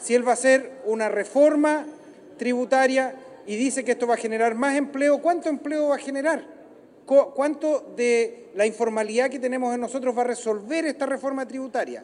En ese sentido, el diputado Daniel Manouchehri (PS) interpeló al ministro por no aportar las cifras exactas de cómo esta medida afectaría el desempleo.